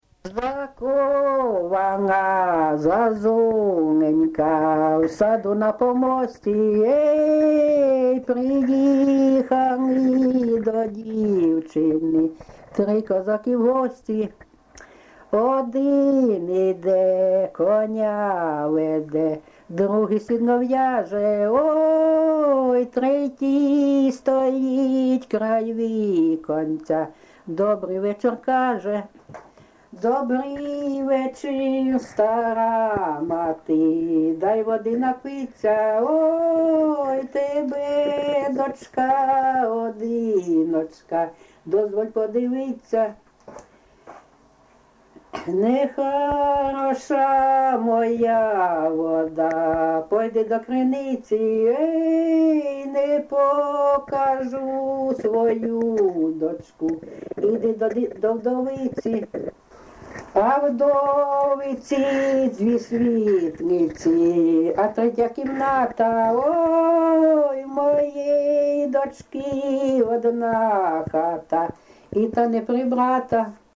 ЖанрПісні з особистого та родинного життя
Місце записус. Курахівка, Покровський район, Донецька обл., Україна, Слобожанщина